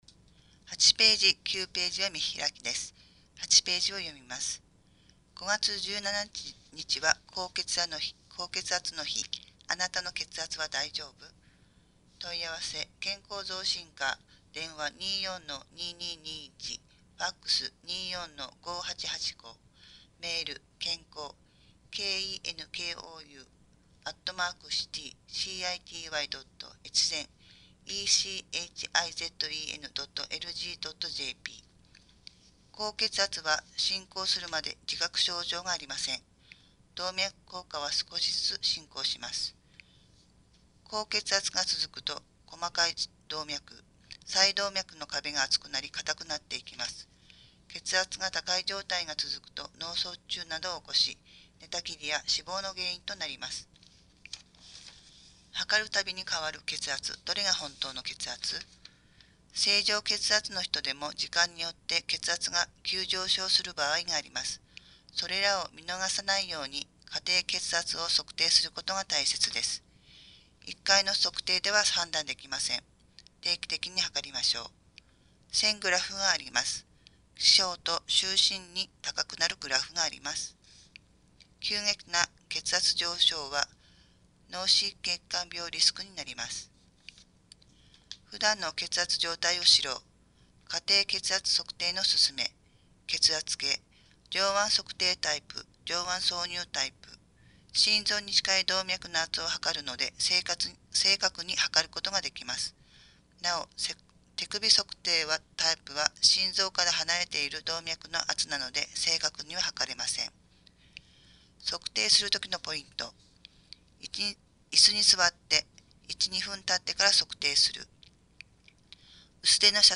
越前市広報５月号（音訳）